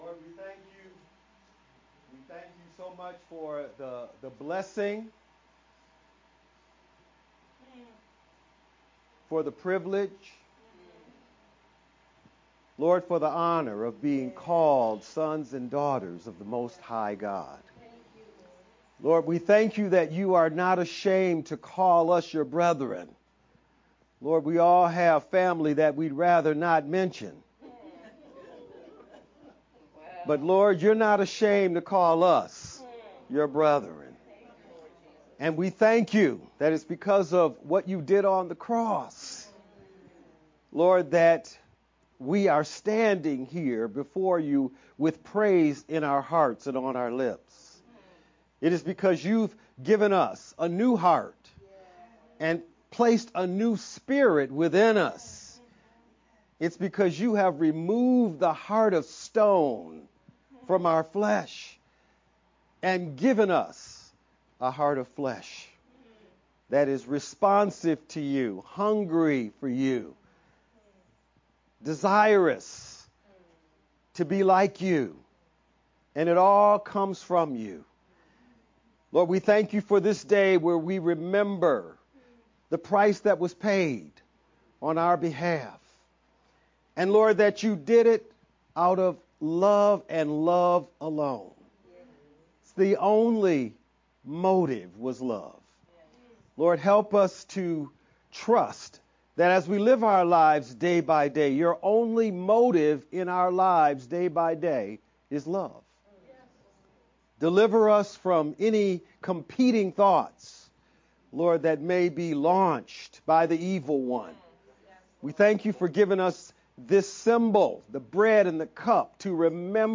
VBCC-6-22-sermon-only-edited_Converted-CD.mp3